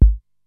VCF BASE 2 3.wav